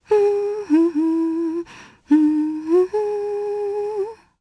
Selene-Vox_Hum_jp.wav